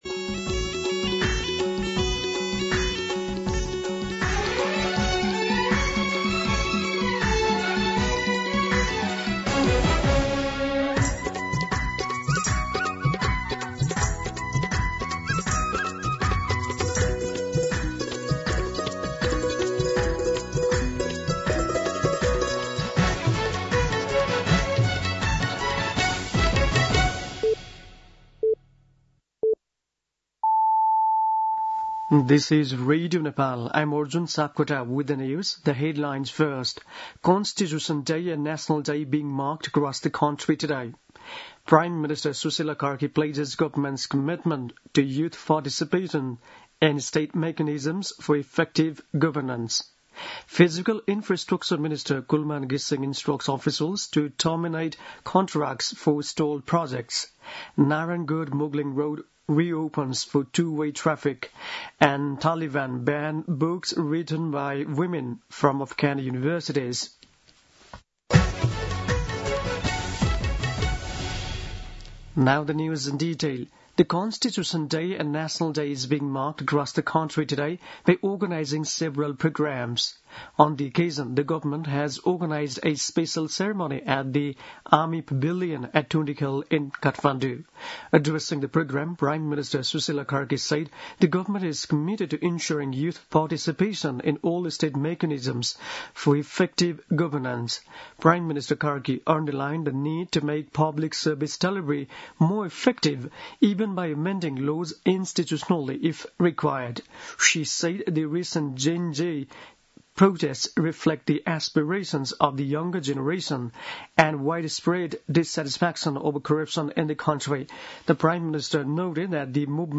An online outlet of Nepal's national radio broadcaster
दिउँसो २ बजेको अङ्ग्रेजी समाचार : ३ असोज , २०८२
2-pm-English-News-4.mp3